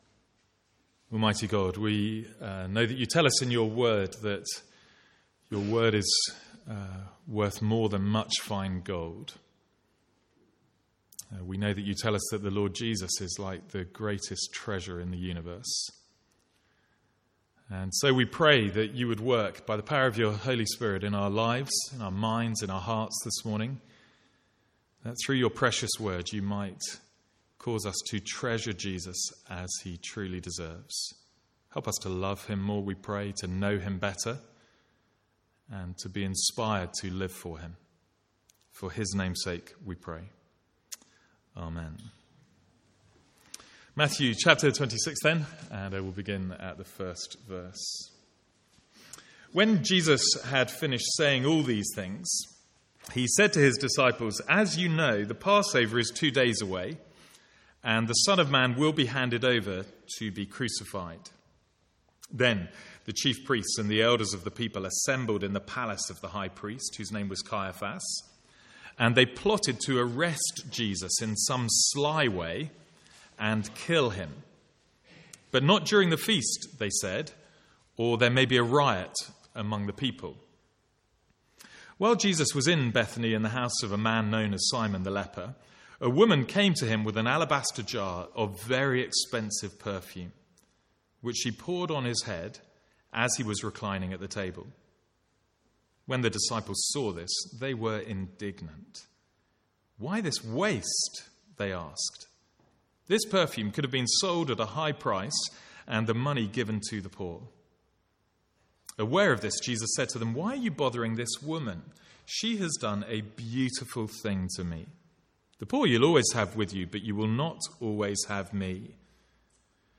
From the Sunday morning series in Matthew.
Sermon Notes